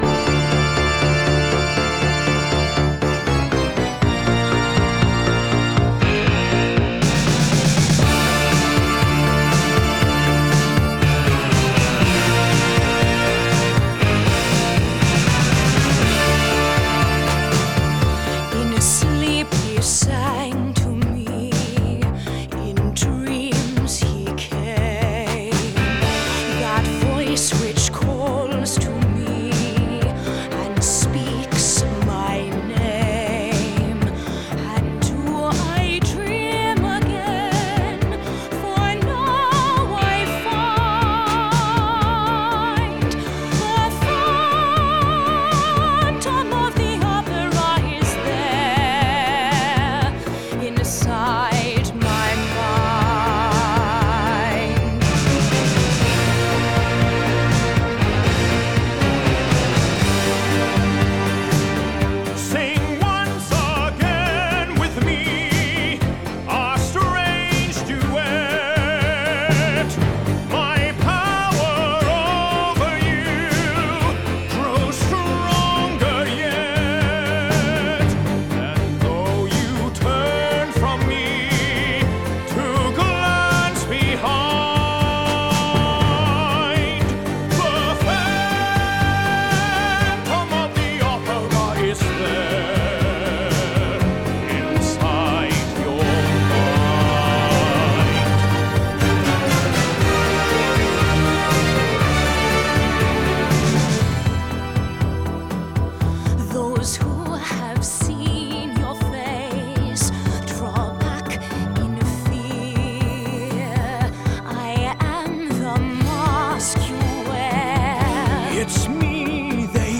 мюзикл